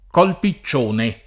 piccione [pi©©1ne] s. m.; f. -napippione [pippL1ne] la più antica forma tosc. (regolare continuaz. del lat. pipio -onis), abbandonata tra ’400 e ’600 in favore della forma piccione di fonetica meridionale — sim. i cogn. Piccione, -ni, Pippione, e i top. Piccione e Col Piccione [